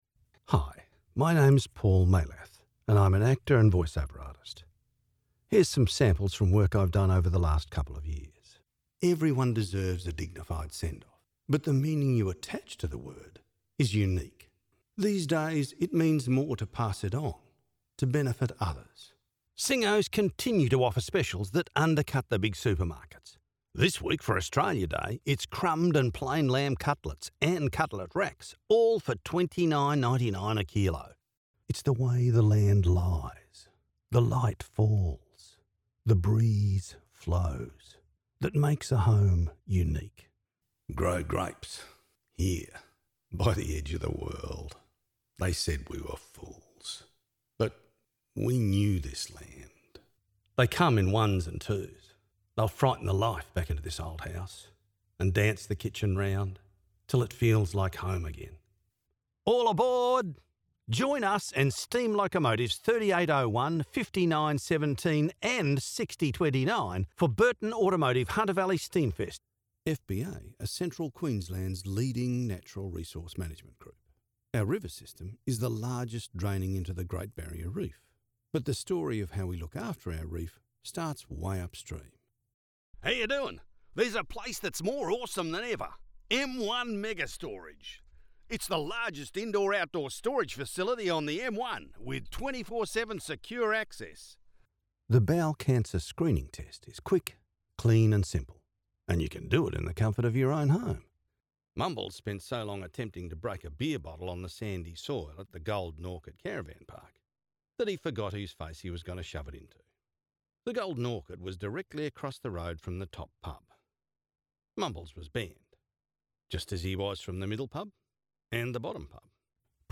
I have the genuine, warm & credible voice that you need to connect with your audience.
My natural voice is neutral British English, clear, friendly and easy to listen to with the ability to vary the dynamics to suit the project.